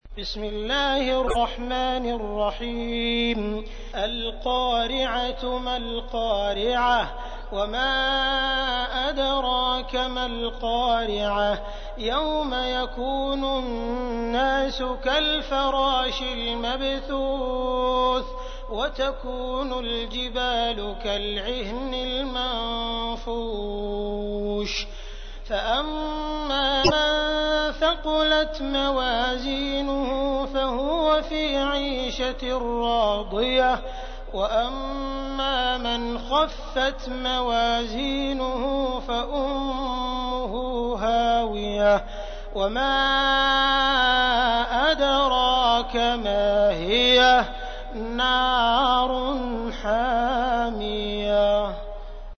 تحميل : 101. سورة القارعة / القارئ عبد الرحمن السديس / القرآن الكريم / موقع يا حسين